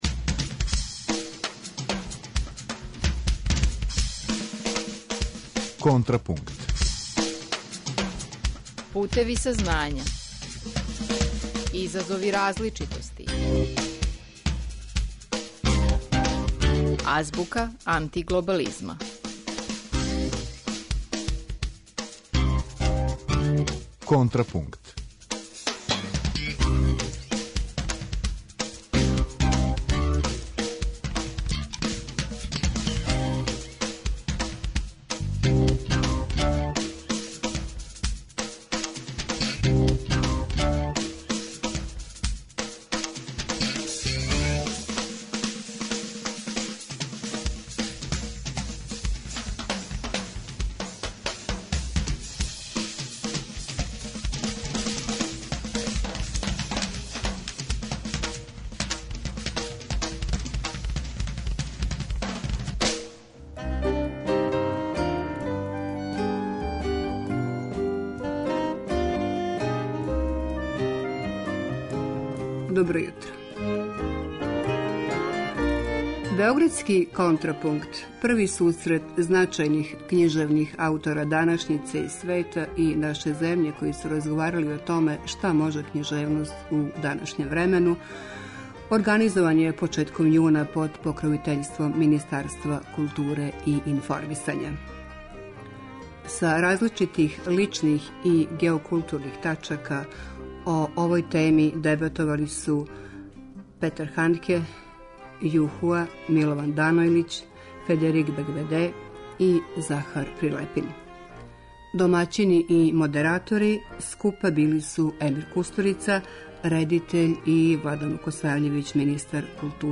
Са различитих личних и геокултурних тачака, о овој теми дебатовали су: Петер Хандке, Ју Хуа, Милован Данојлић, Федерик Бегбеде и Захар Прилепин.